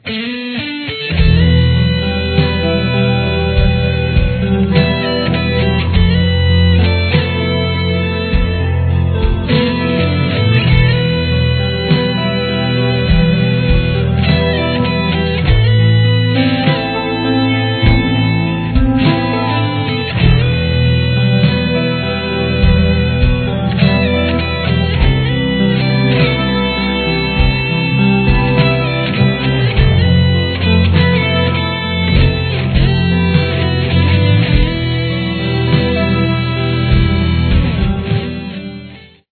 Here’s what it sounds like all together: